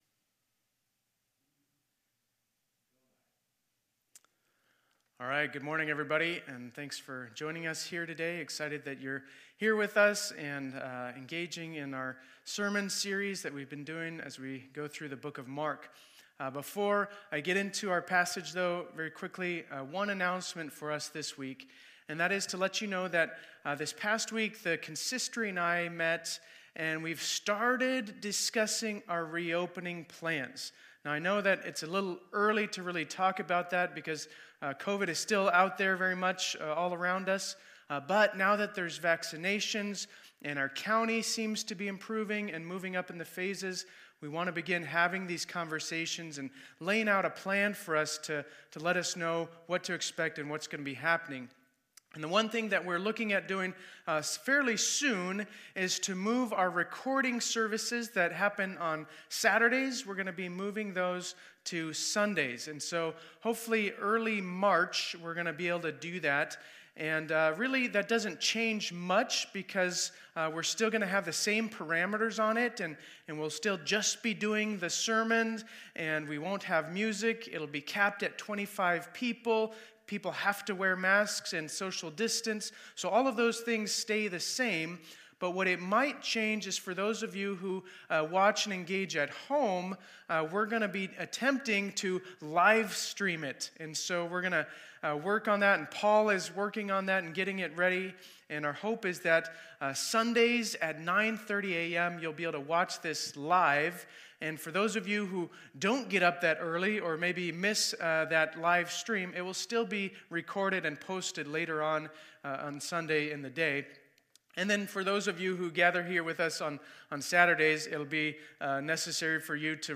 This weeks Sermon Video has a number of issues relating to the tech team